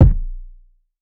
Royalty Kick.wav